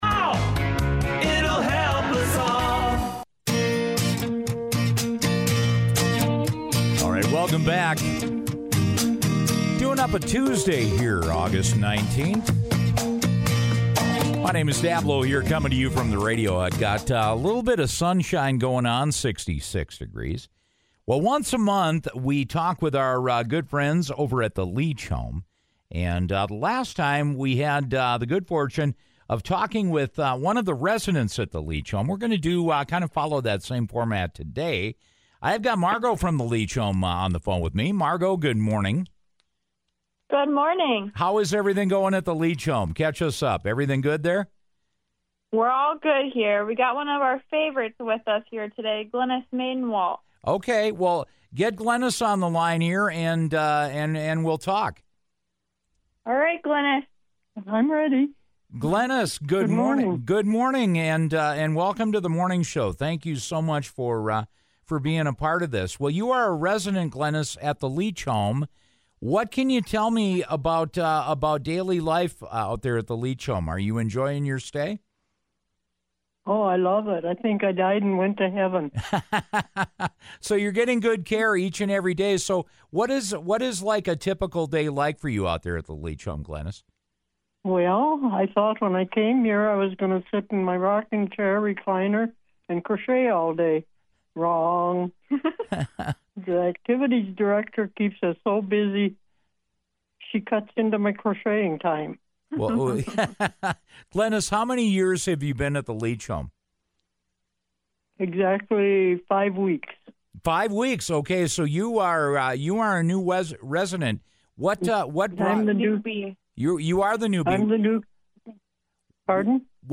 Today I had the good fortune of talking with another happy and joyful Leach Home resident!